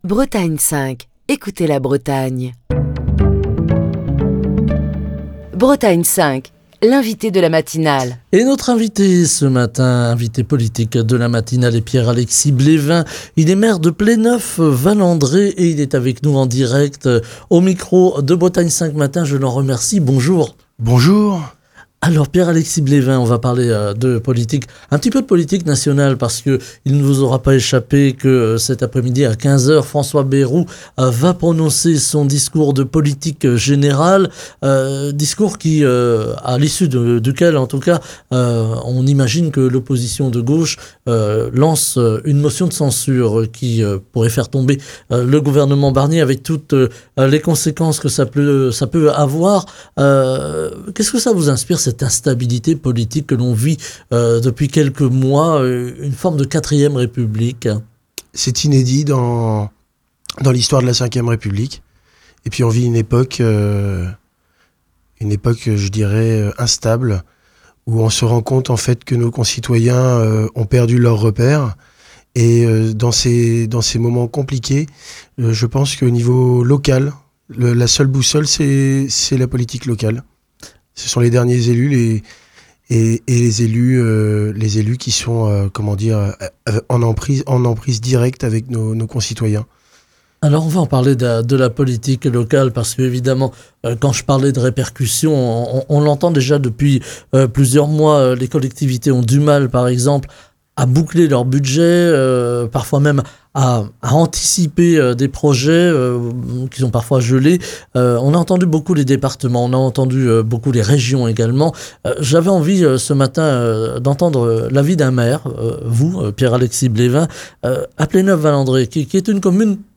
Ce mardi, Pierre-Alexis Blévin, maire de Pléneuf-Val-André était l'invité politique de la Matinale de Bretagne 5, pour aborder les enjeux politiques actuels et les défis auxquels sont confrontées les collectivités locales.